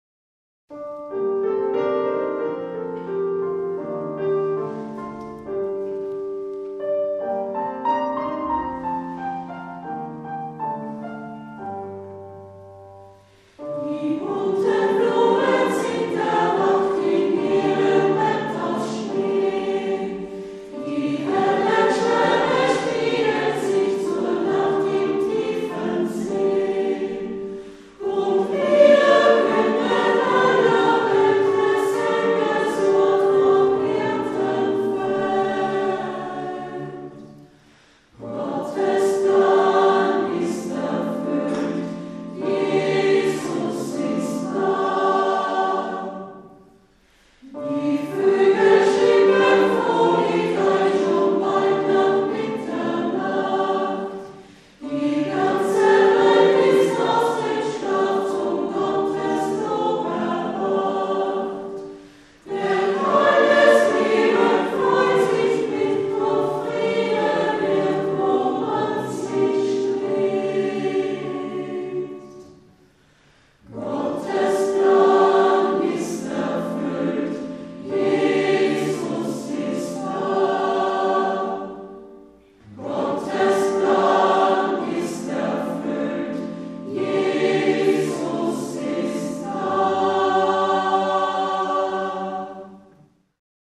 Klavier, Gesang
Blockflöte, Gesang
Sopransaxophon, Gesang